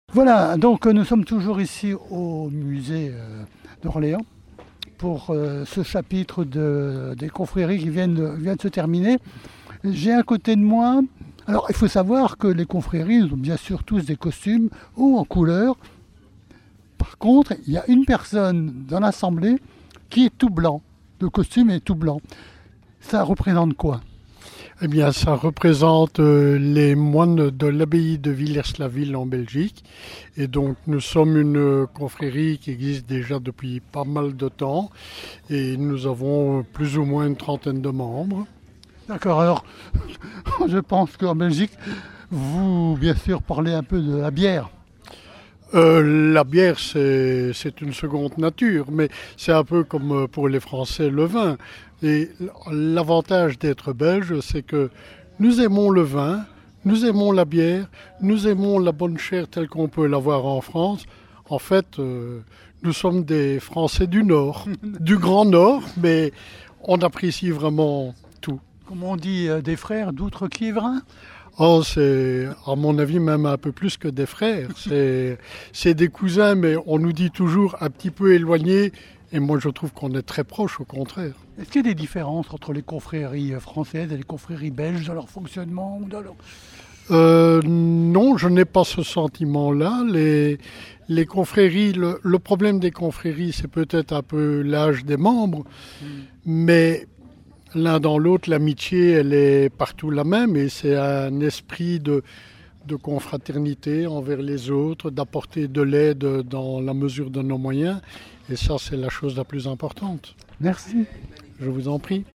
VAG MUSIC-CONFRERIES INTERVIEW NO 3